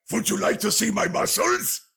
reinhardt-see-my-muscles.ogg